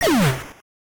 Pkmn faint.ogg